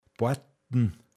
pinzgauer mundart
warten boatn
heapassn